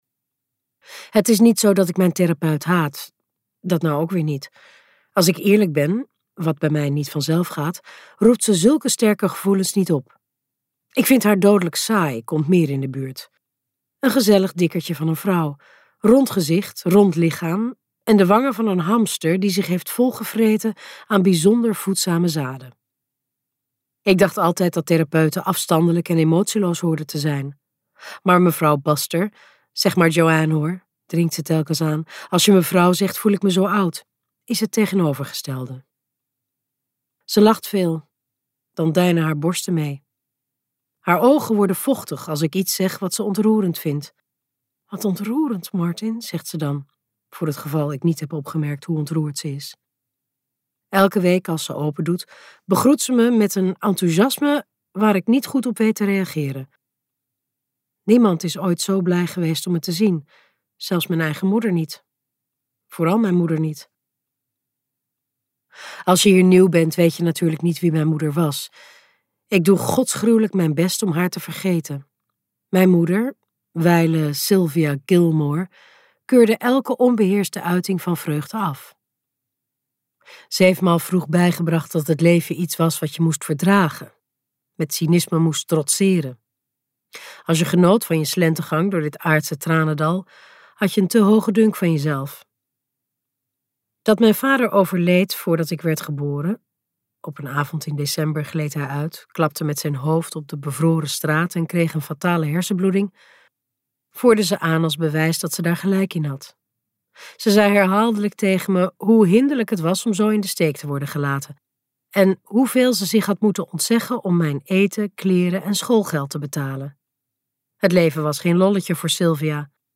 Ambo|Anthos uitgevers - Een van ons luisterboek